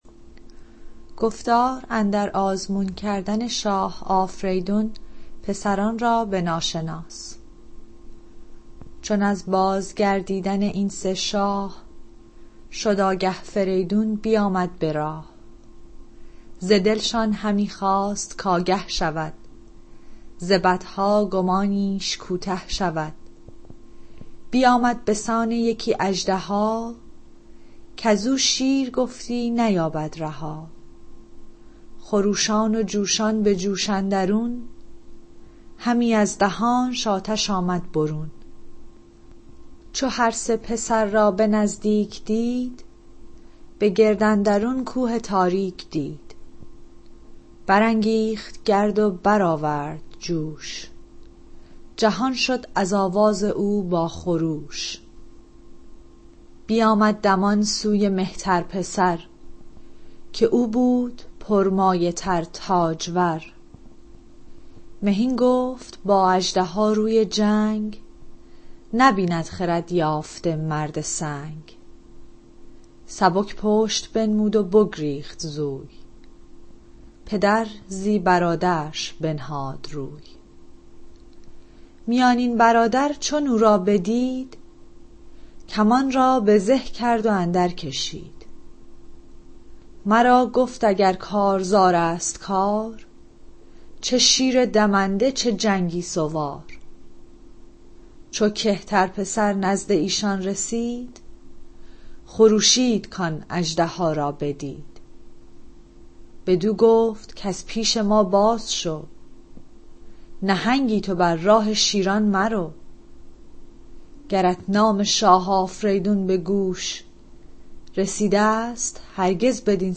روایت شده از شاهنامۀ دکتر خالقی مطلق